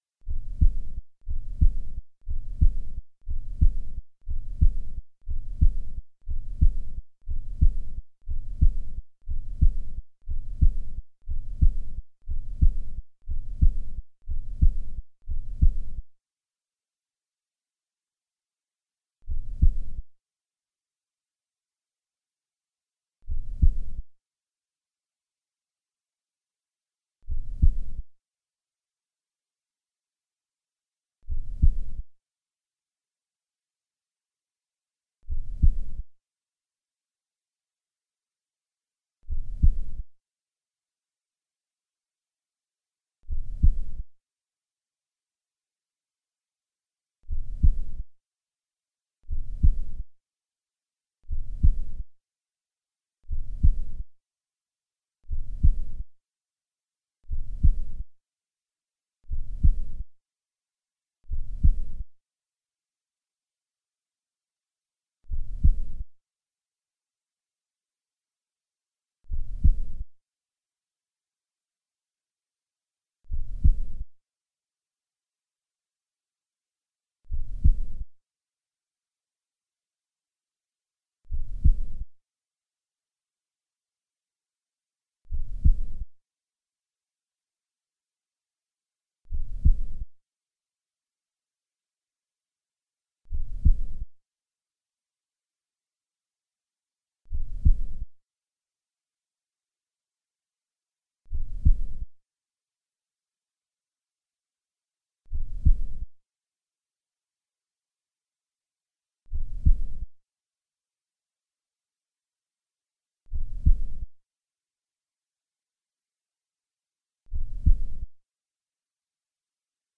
Netbeat es una instalación sonora presentada en la primera edición de ArteShop Bilbao en el 2012.
Así, mientras el usuario del centro pasea por sus espacios y tiendas puede escuchar un audio que reproduce un latido que cambia a partir del numero de visitas que tenga la página web de este centro comercial, representando la vida virtual de este espacio.
beats.mp3